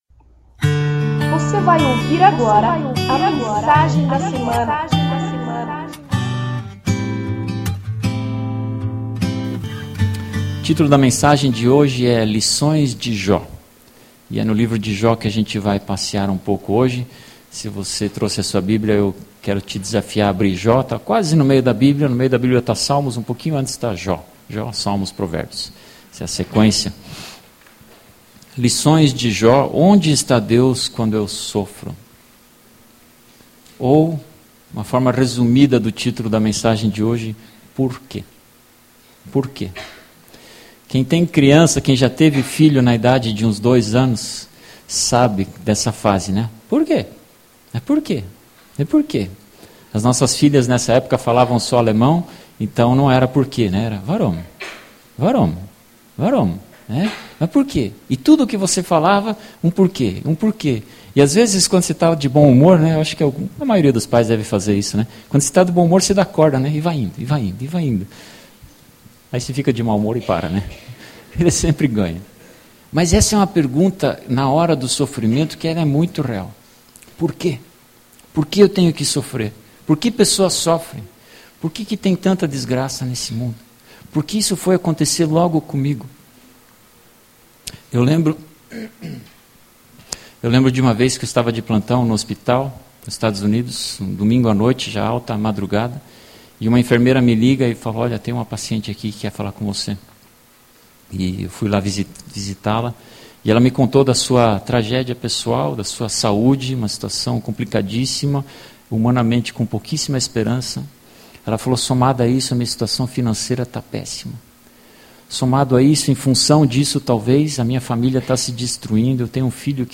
Confira a mensagem em áudio deste domingo (29.01.12).